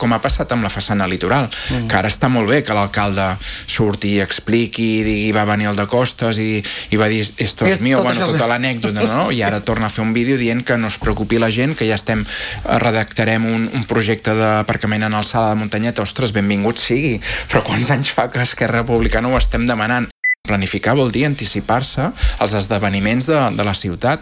Ho ha dit el portaveu republicà i cap de l’oposició, Xavier Ponsdomènech, en una entrevista al matinal A l’FM i + de Ràdio Calella TV.